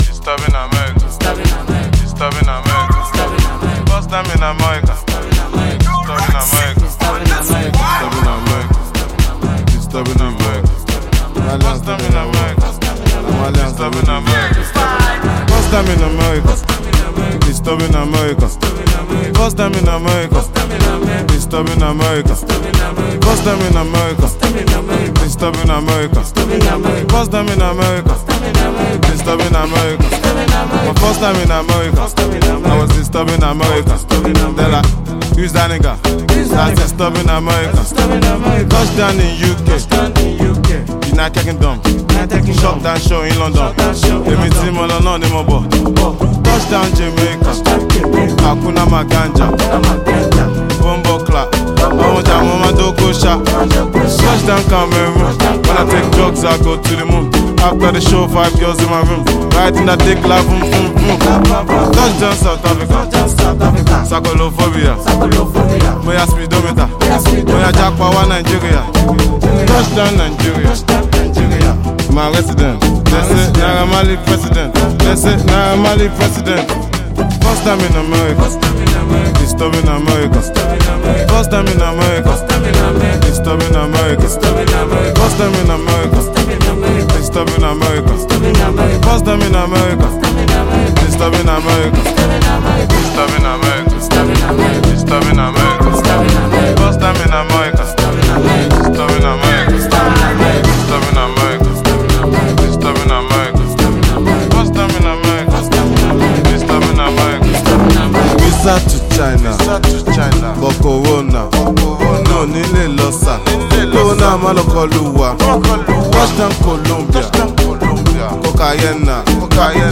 British-Nigerian singer and songwriter
African Music